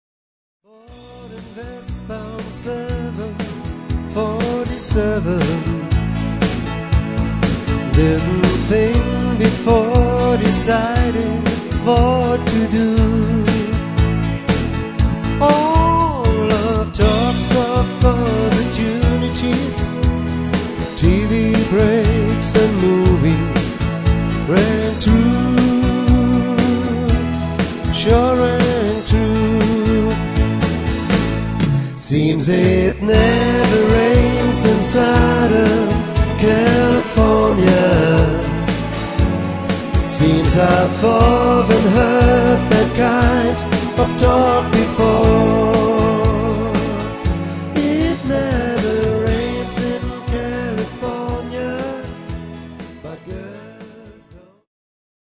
Tanzmusik und Diskothek f�r Ihre Familienfeier oder Party .